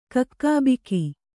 ♪ kakkābiki